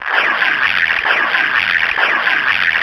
77 TEAR.wav